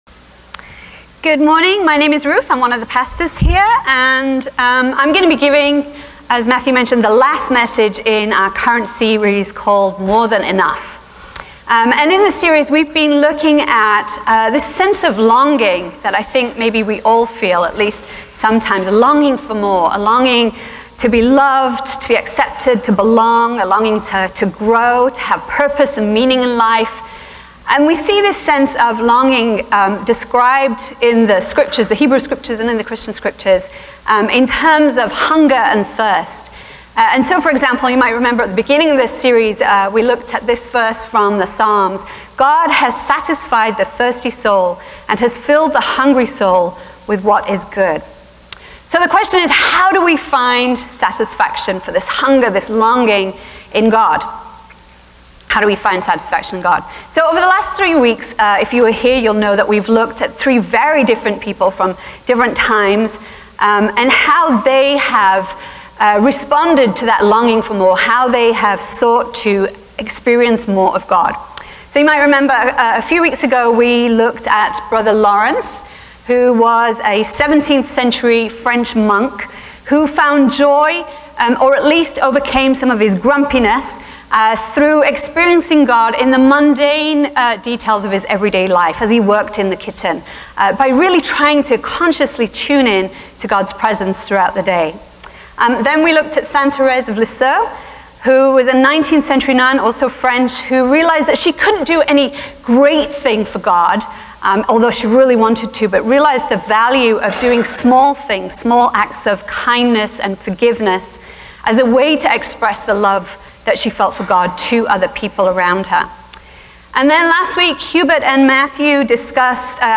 A message from the series "Stories of Faith." In this last week of the series, we explored the story in Luke's Gospel of the woman who washed Jesus' feet.